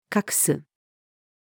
隠す-female.mp3